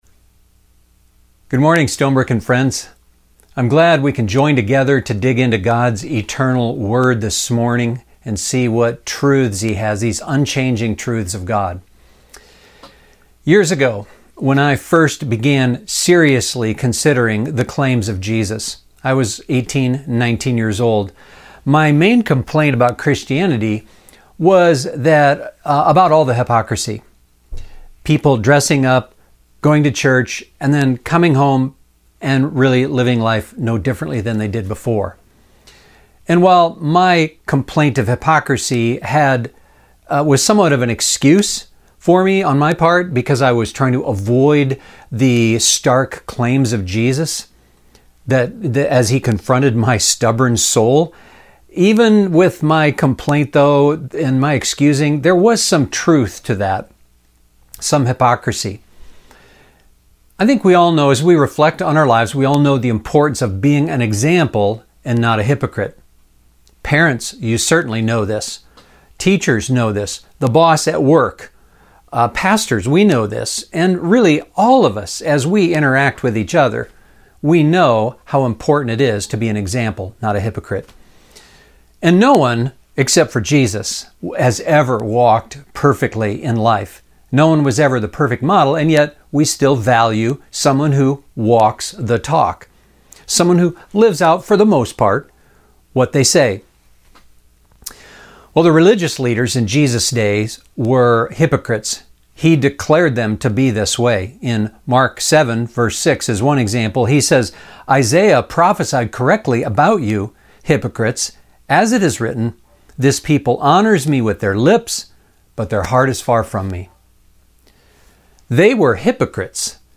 We are in our second week of a sermon series going through a letter written by the Apostle Paul to a church in ancient Greece.